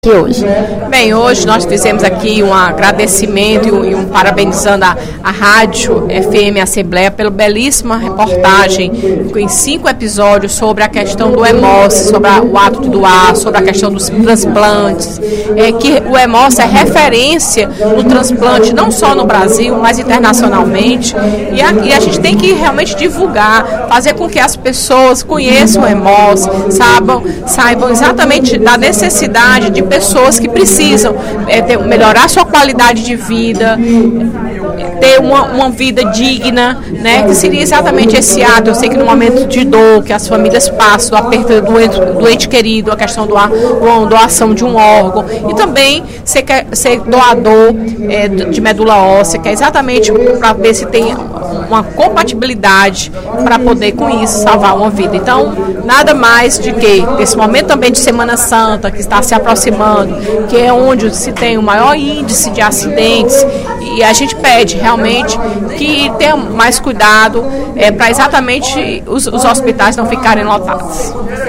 Durante o primeiro expediente da sessão plenária desta terça-feira (31/03), a deputada Fernanda Pessoa (PR) parabenizou a série de reportagens da FM Assembleia intitulada “Os caminhos da doação de órgãos”.
Em aparte, o deputado Ferreira Aragão (PDT) parabenizou o discurso e afirmou que a campanha de conscientização sobre a doação de órgãos é um desafio, mas precisa ser realizada.